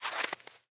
Minecraft Version Minecraft Version snapshot Latest Release | Latest Snapshot snapshot / assets / minecraft / sounds / block / powder_snow / step4.ogg Compare With Compare With Latest Release | Latest Snapshot
step4.ogg